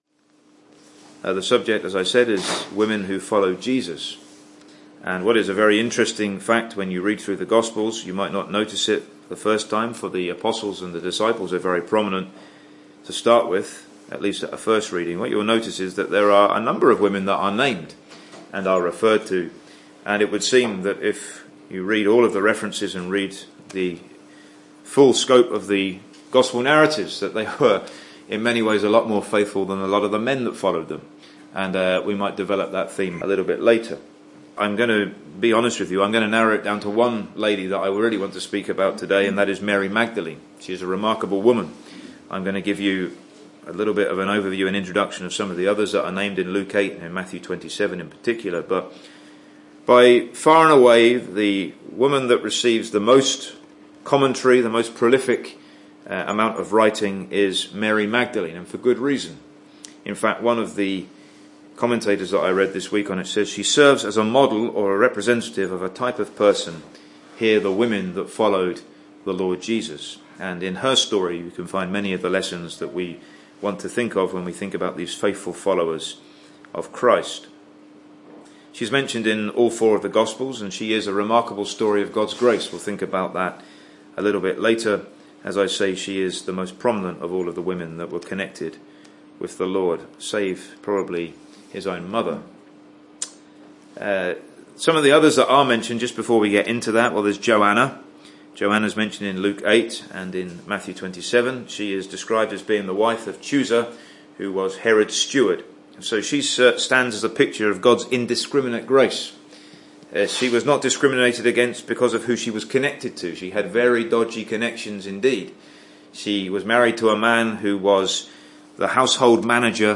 She exemplified the power of regeneration; she identified with Christ in His crucifixion; she testified of Christ in His resurrection (Message preached 12th Nov 2017)